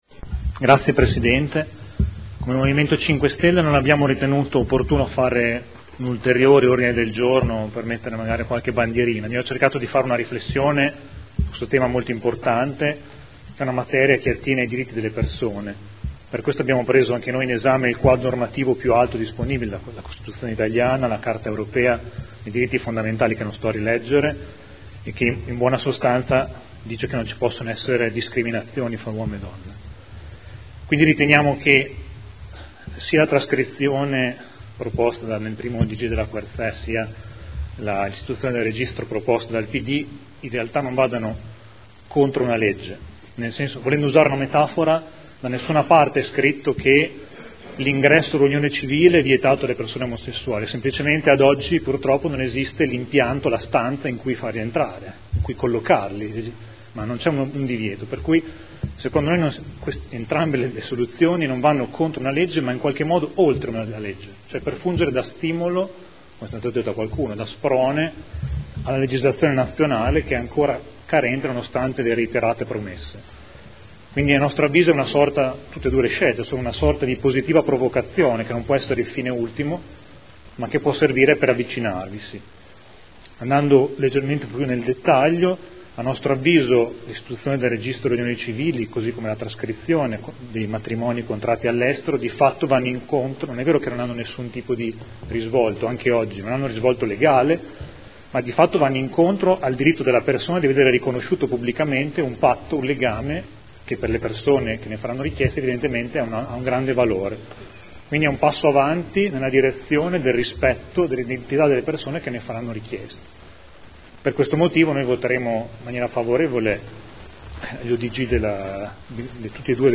Seduta del 23/10/2014. Dibattito sulle mozioni presentate, inerenti la tematica del registro unioni civili